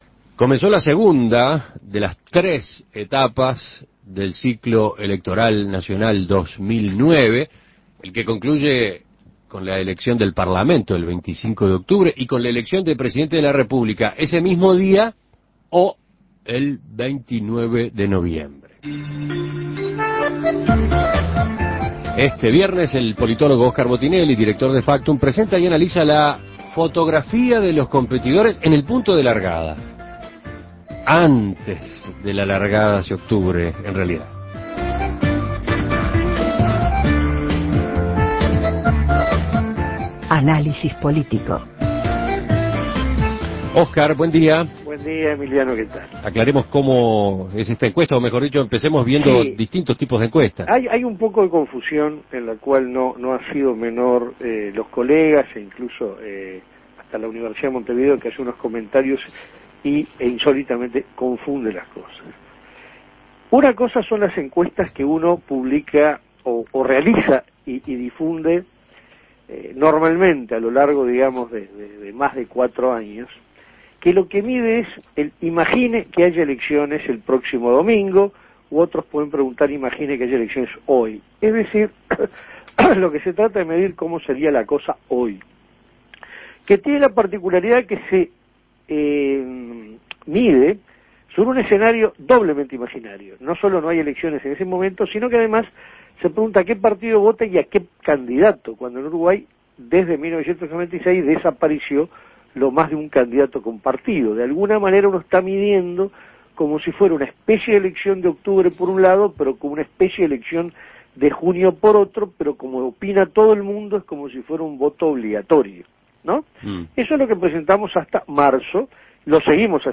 Análisis Político ¿A quién se inclinaría a votar en las elecciones de octubre, si estas fueran las fórmulas presidenciales de los principales partidos?